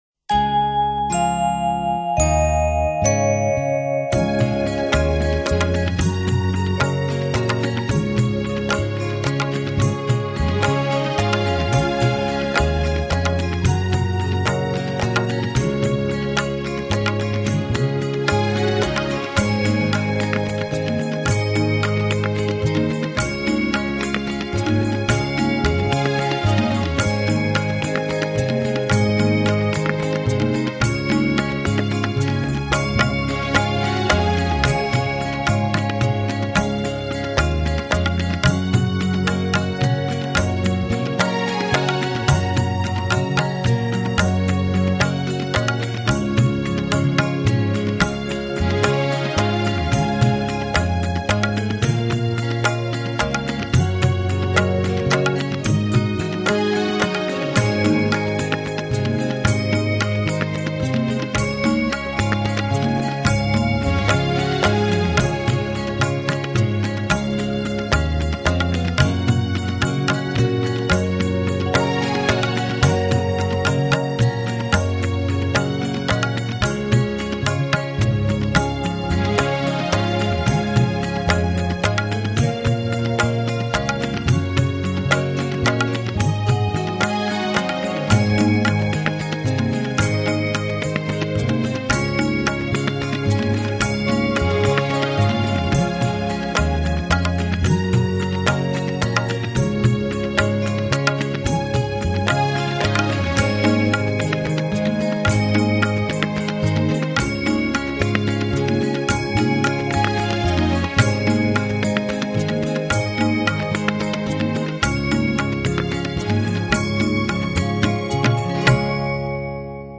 Song-Music-only-2.mp3